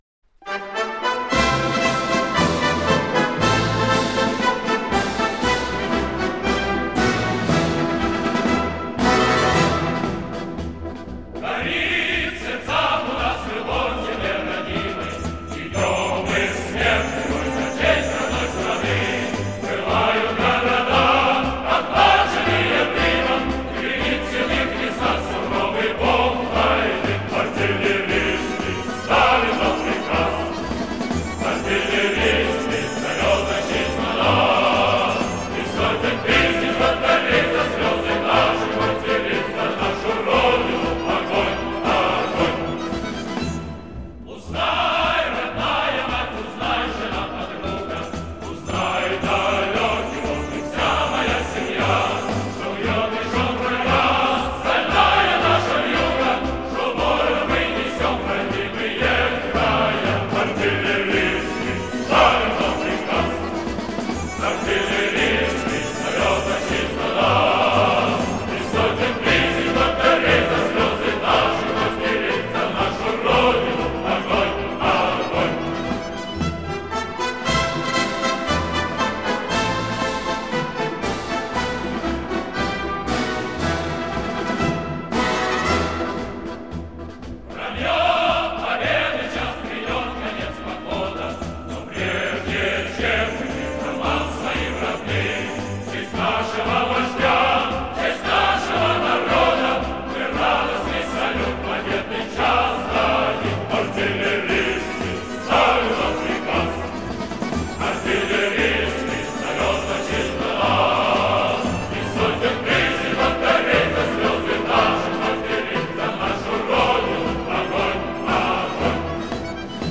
Отличное качество.